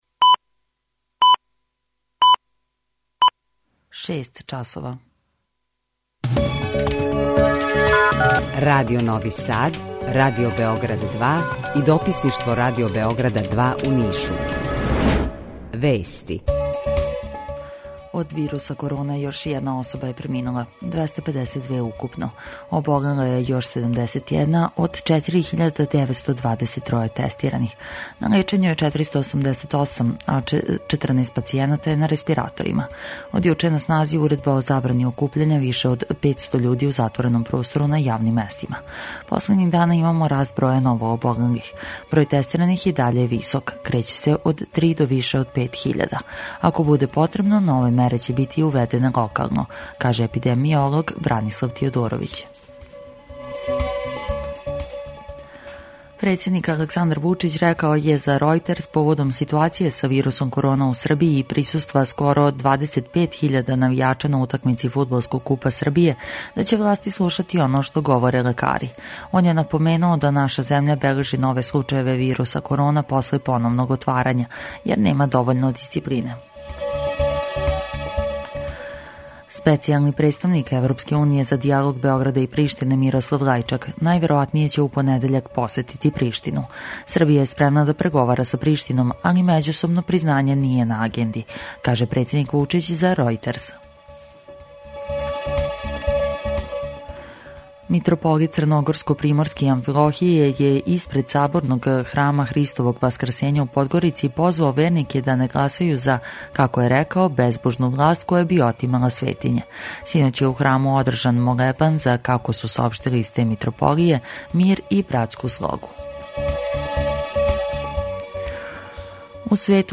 Укључење из Бања Луке
У два сата, ту је и добра музика, другачија у односу на остале радио-станице.